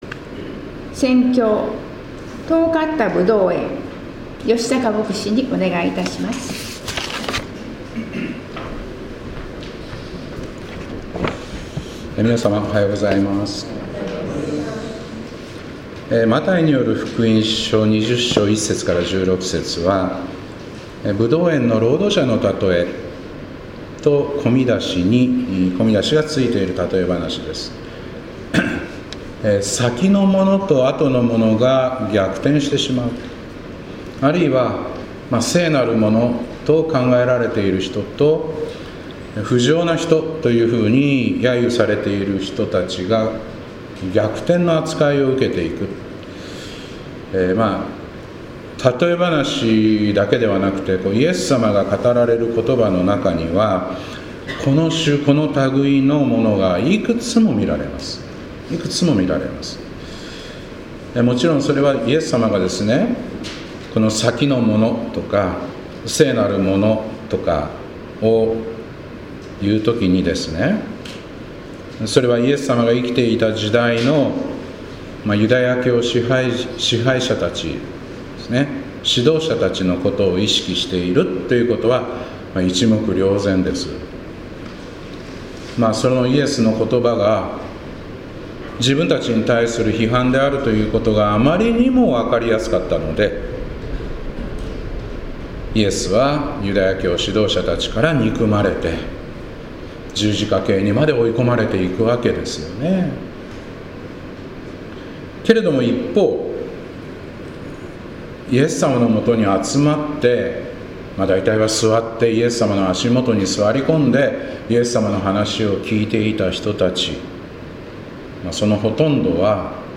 2025年3月9日礼拝「遠かったぶどう園」